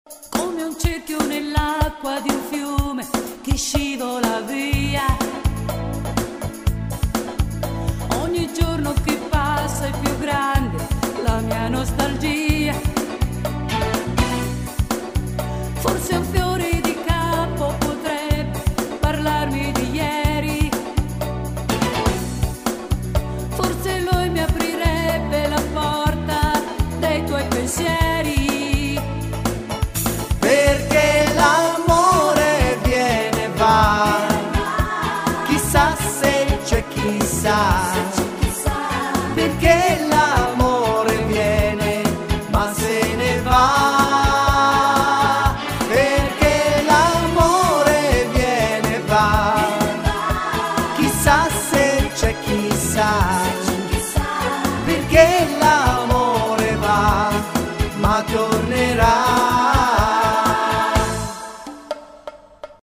disco music anni 80